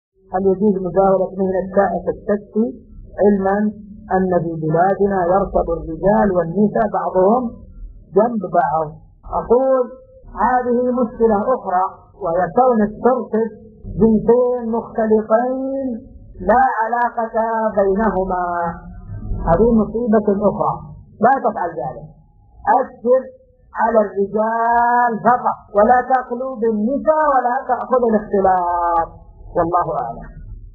السؤال مقتطف من شرح كتاب الصيام من زاد المستقنع .